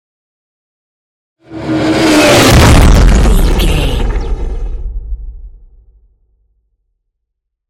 Scifi passby whoosh 431
Sound Effects
futuristic
high tech
intense
pass by